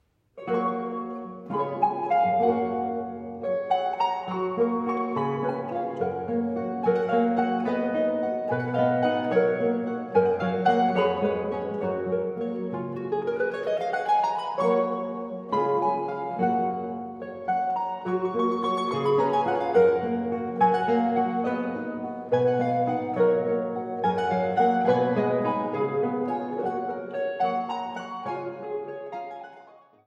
Laute & Mandoline
Liuto Forte, Konzertgitarre